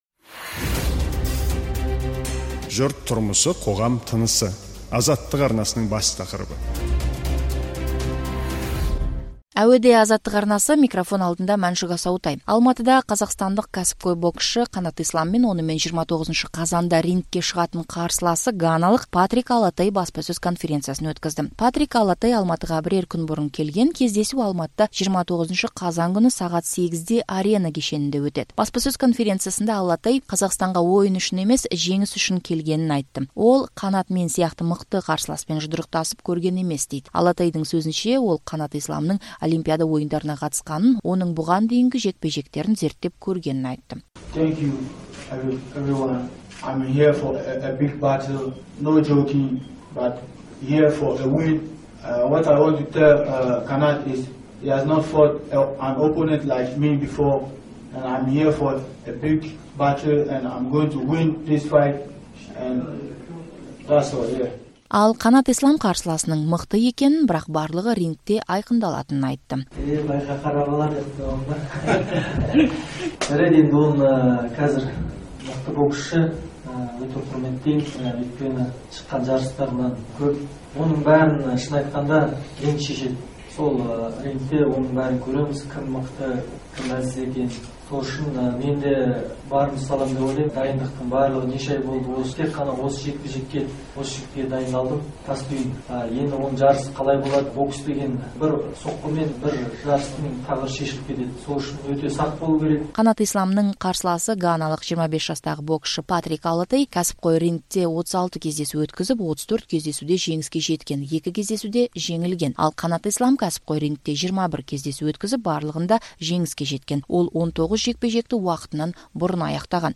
Алматыда қазақастандық боксшы Қанат Ислам мен онымен 29 қазанда рингке шығатын қарсыласы ганалық Патрик Аллотей баспасөз жиынын өткізді.